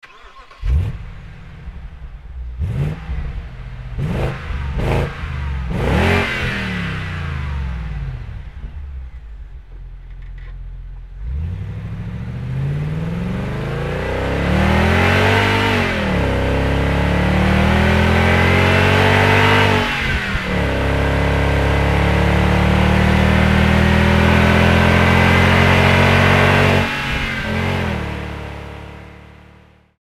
• 豪华SUV的代表车型，同是V8 4.8升发动机,但声音明显不同，明显更为彪悍、凶猛，甚至是暴虐。